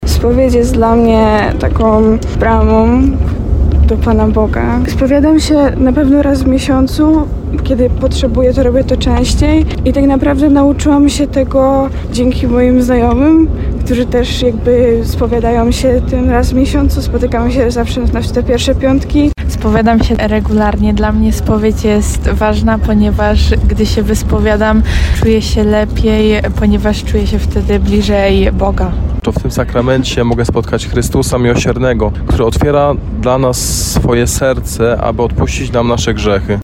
Młodzi z diecezji tarnowskiej, którzy są w Rzymie mówią o ważnej roli sakramentu pokuty i pojednania.
1mlodzi_sonda.mp3